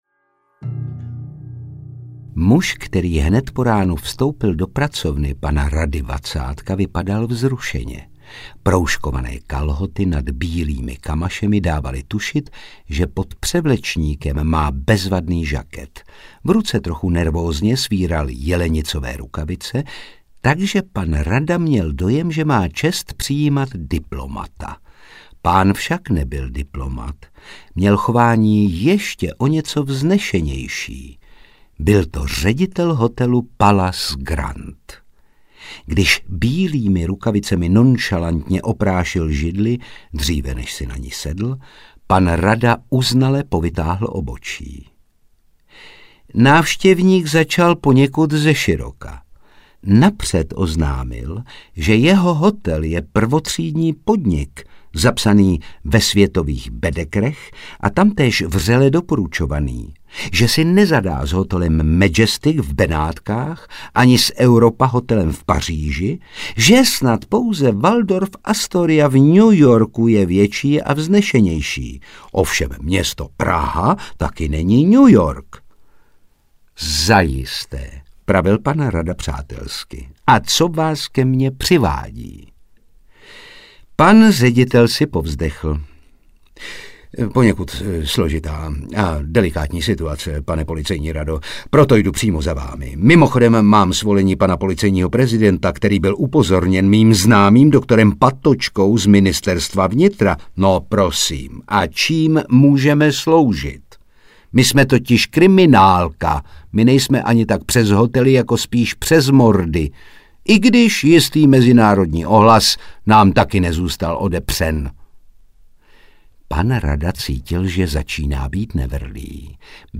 Panoptikum hříšných lidí audiokniha
Ukázka z knihy
• InterpretJaromír Meduna, Saša Rašilov, Otakar Brousek ml.